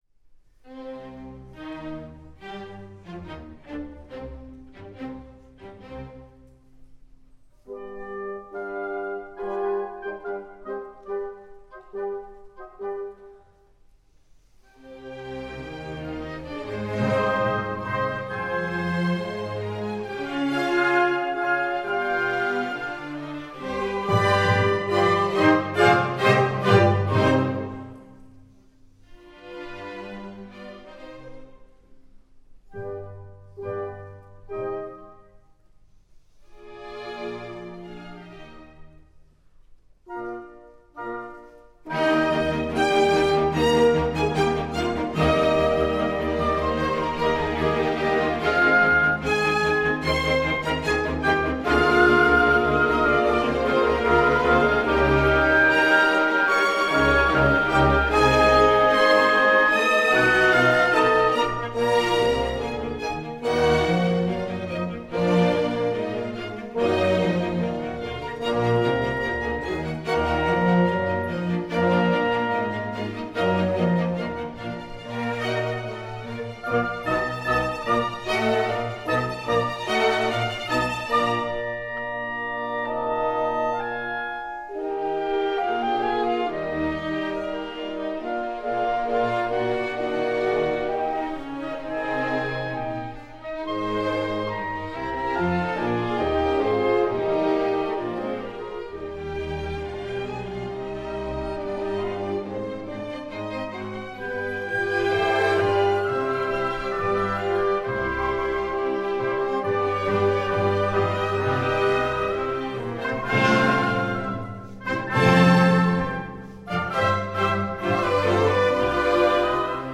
Piano and Ensemble
Style: Classical
piano, conductor